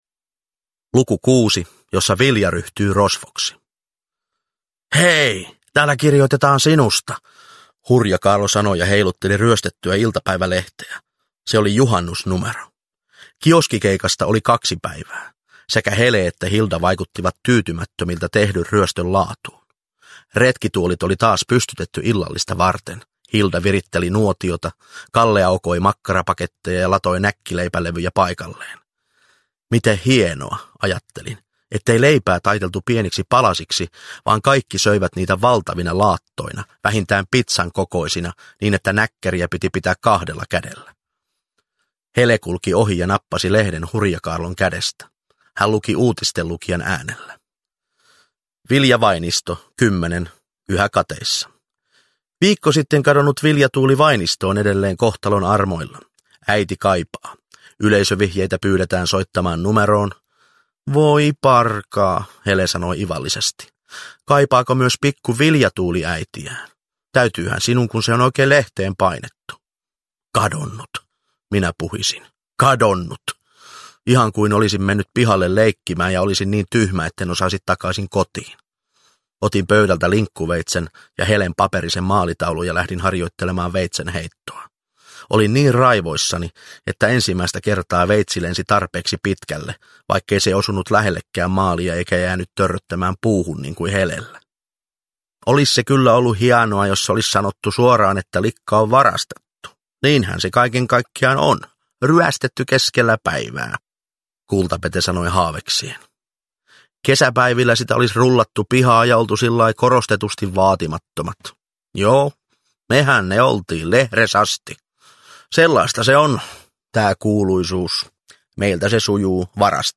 Uppläsare: Hannu-Pekka Björkman